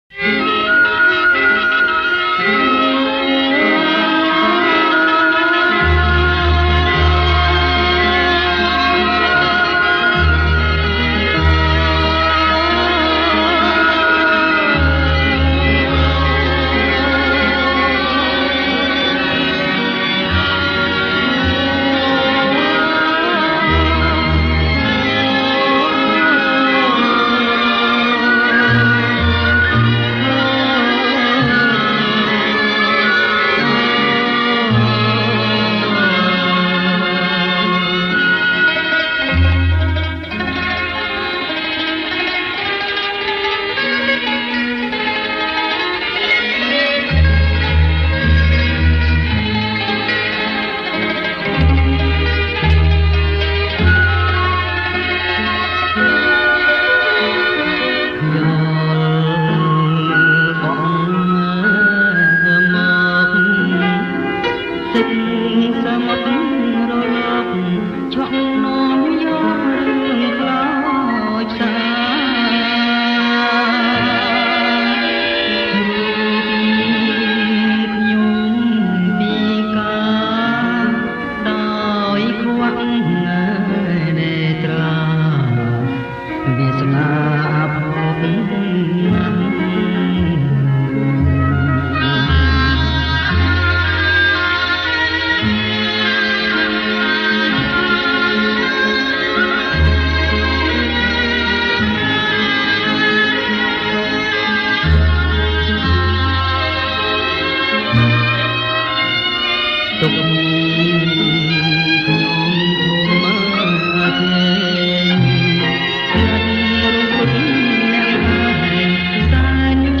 • ប្រគំជាចង្វាក់ Blue